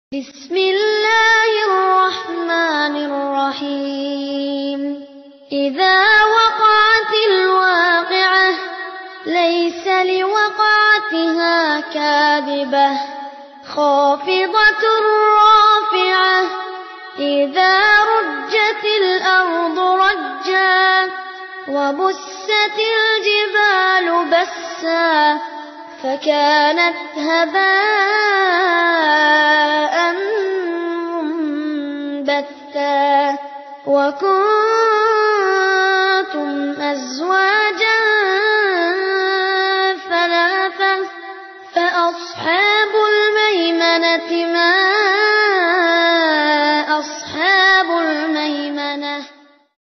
Qur'an#murotal # surat waqi'ah# suratke56#CapCut